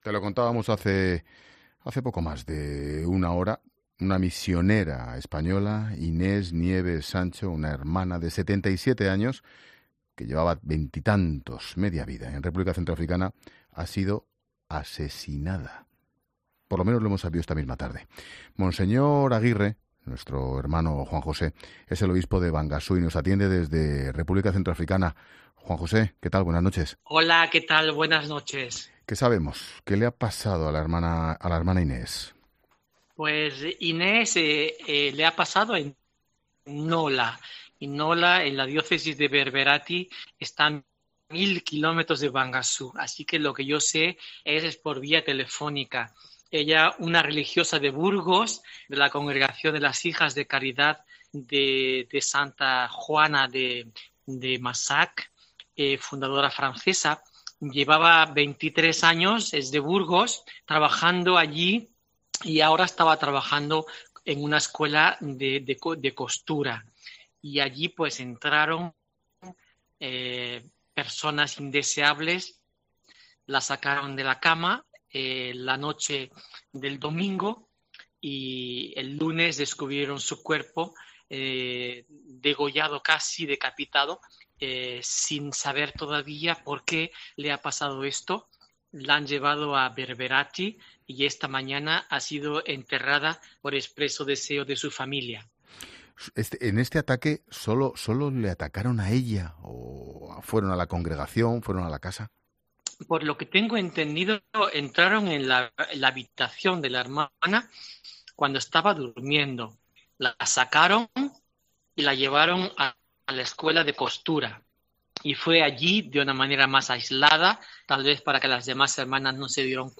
En una entrevista en el programa "La Linterna" con Ángel Expósito, Aguirre ha señalado que entraron a su habitación "personas indeseables, la sacaron de la cama y el lunes la descubrieron casi decapitada.